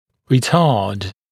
[rɪ’tɑːd][ри’та:д]замедлять, задерживать, тормозить (развитие)
retard.mp3